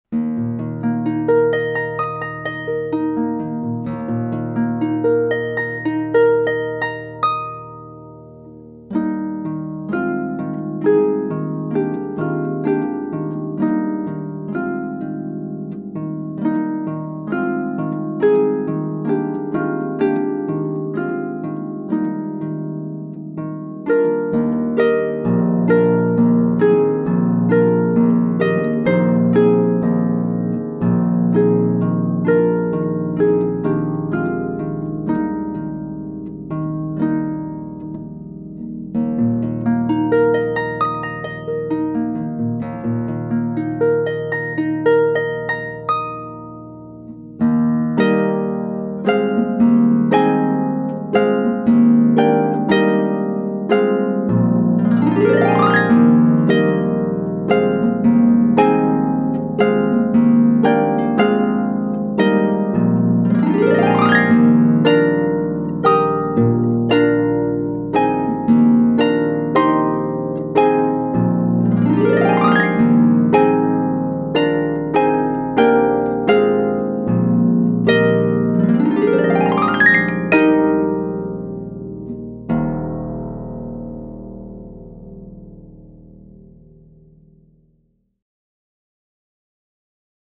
Harpist - The Piano Man's DJ Productions
Piano Man’s DJ Productions is proud to offer a live harpist for your ceremony and/or cocktail hour.
processional.mp3